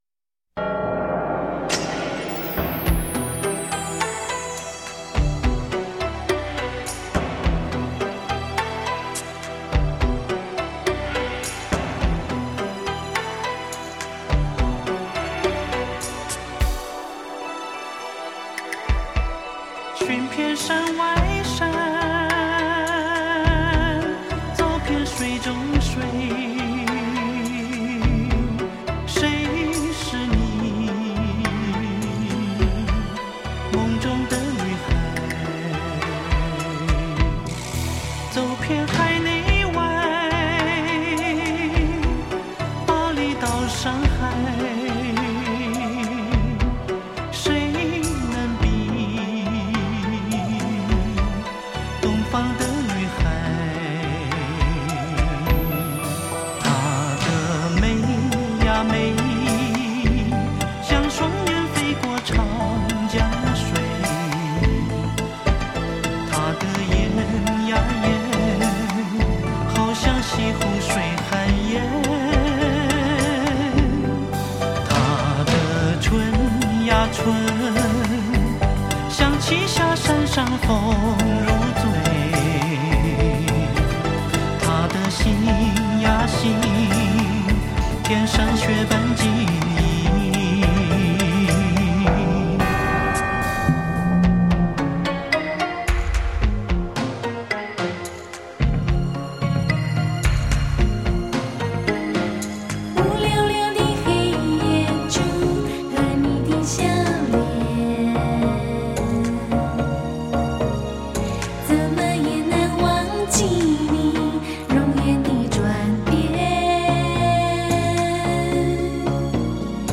45就是 采45转快转的方式演唱串联当红歌曲的组曲 记录着70.80年代台湾流行乐史